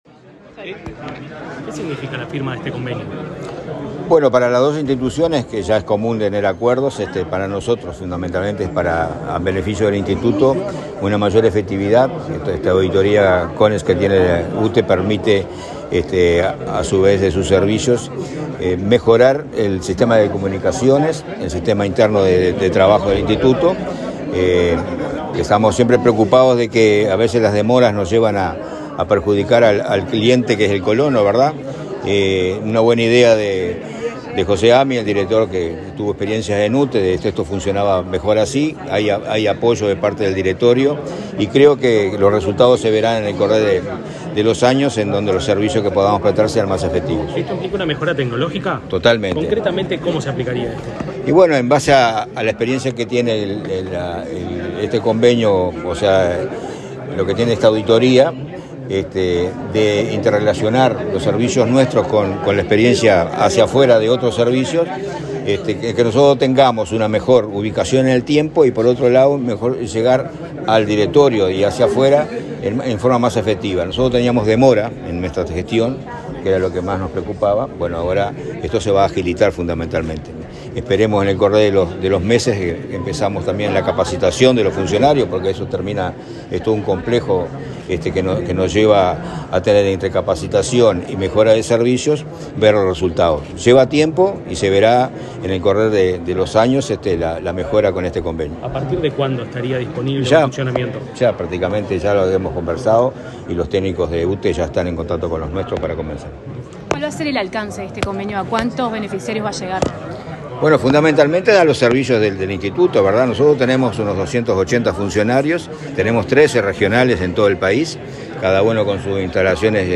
Declaraciones del presidente del INC, Julio Cardozo
El titular del organismo, Julio Cardozo, dialogó con la prensa sobre el alcance del acuerdo.